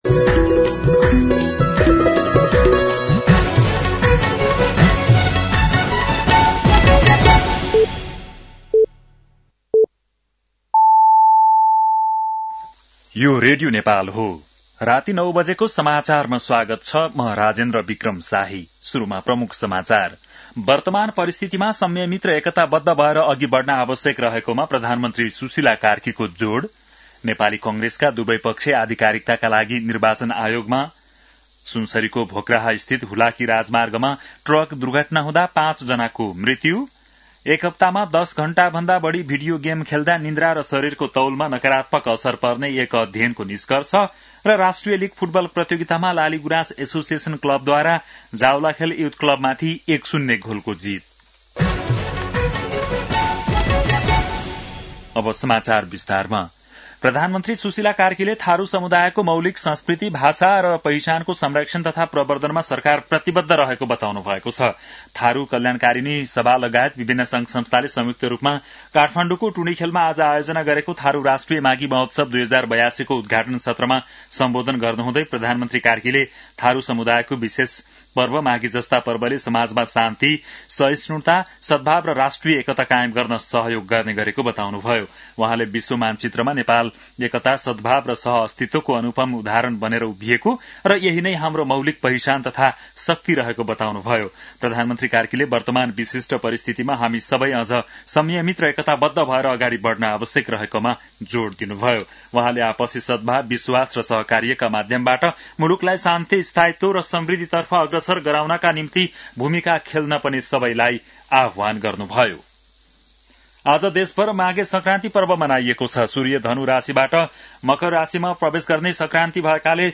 बेलुकी ९ बजेको नेपाली समाचार : १ माघ , २०८२
9-PM-Nepali-NEWS-.mp3